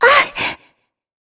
Shea-Vox_Attack2.wav